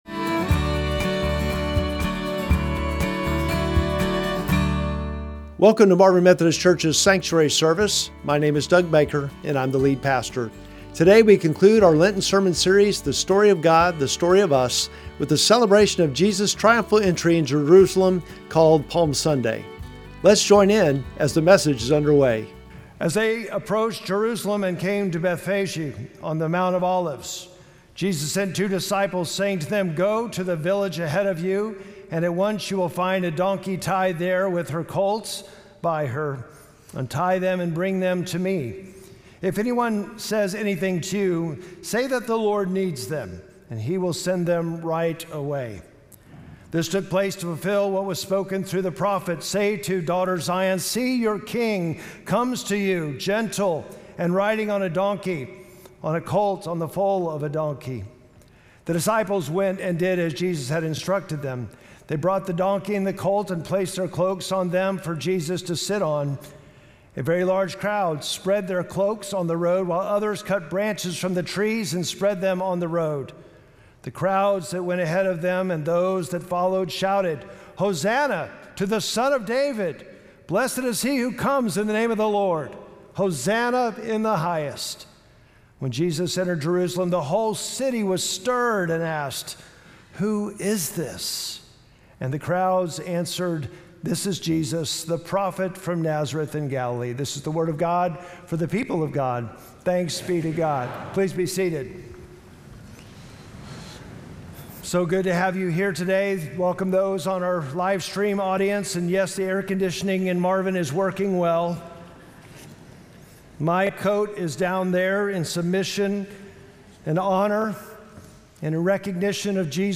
Sermon text: Matthew 2:1-11